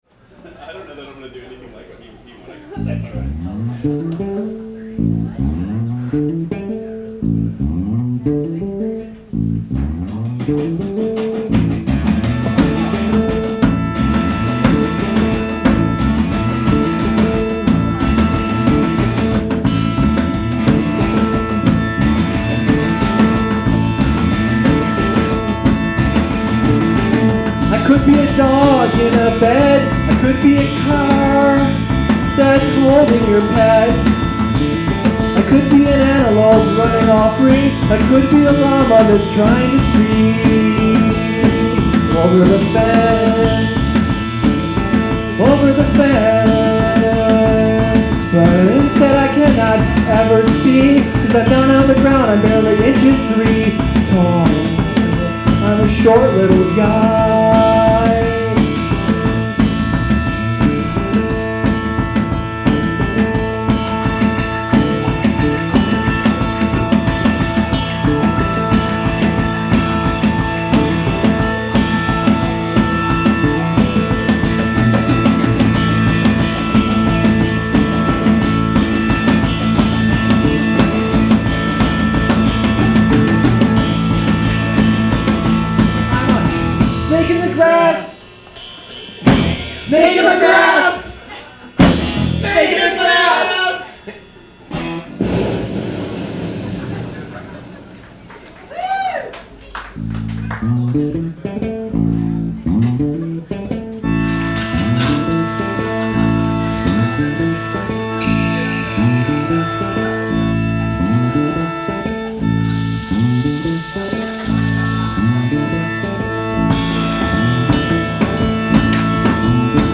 100% Improvised Live Songs